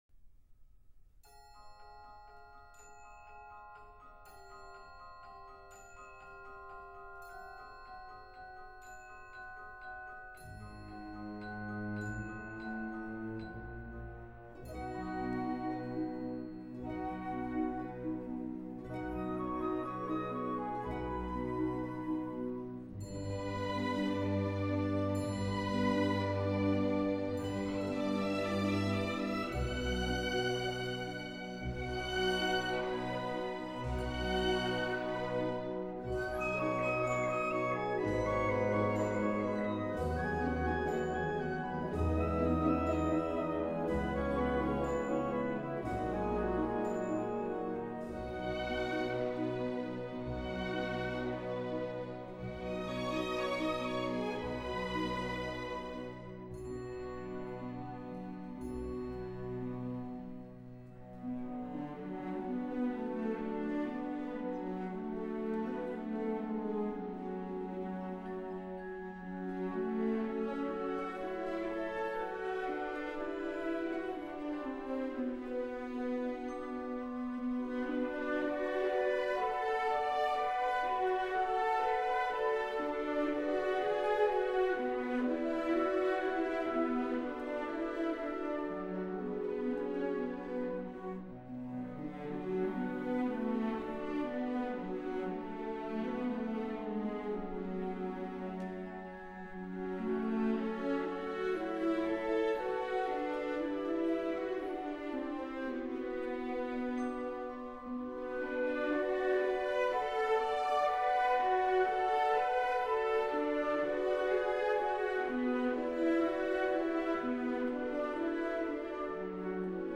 按照大型管弦乐团的器乐编制以及演奏要求改编出这套《音乐会组曲》